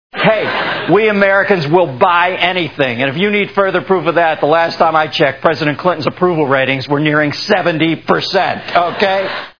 Dennis Miller Live TV Show Sound Bites